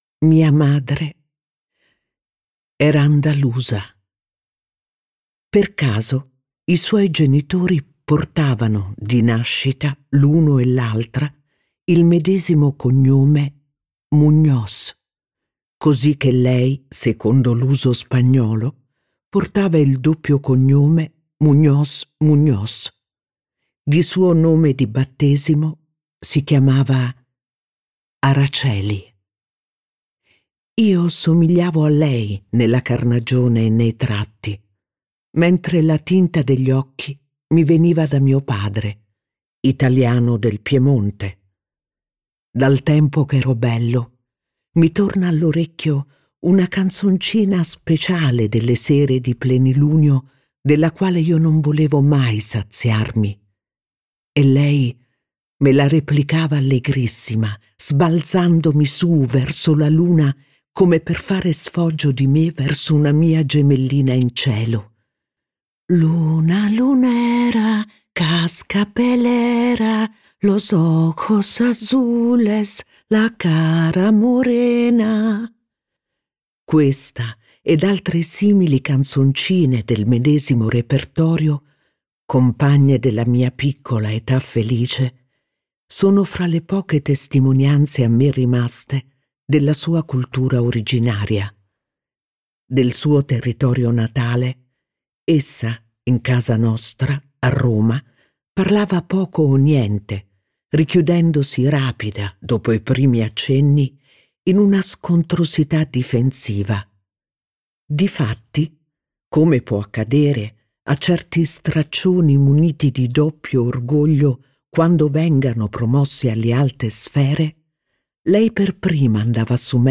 letto da Iaia Forte
Versione audiolibro integrale